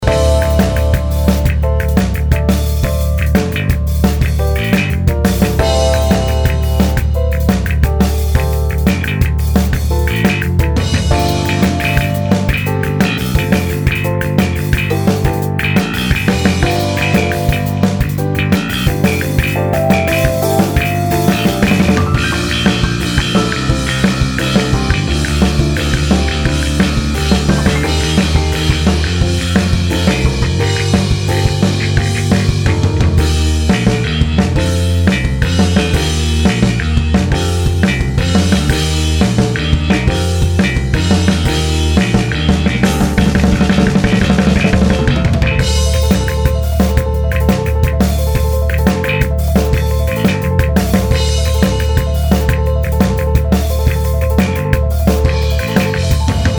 まともに1周コピーする前にギターで好き放題展開作って先に3周目ができちゃったので 原作再現度はかなりいい加減。